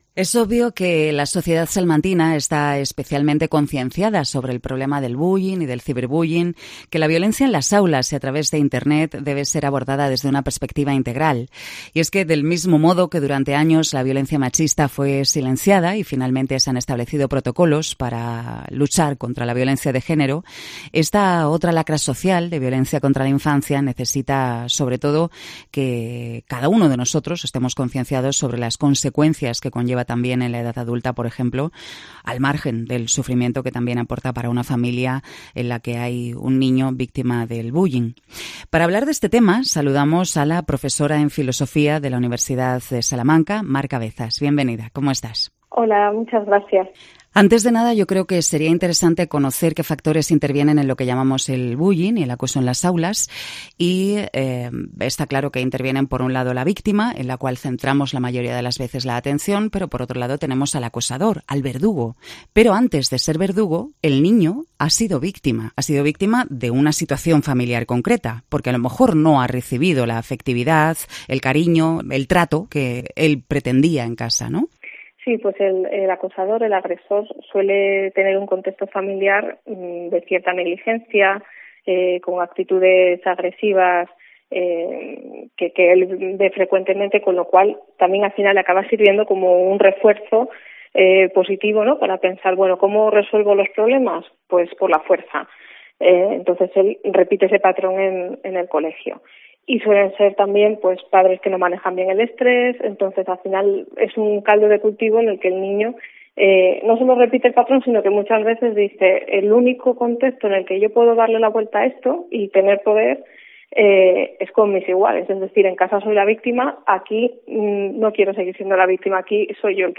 Cope Salamanca entrevista a la doctora en Filosofía y profesora de Universidad de Salamanca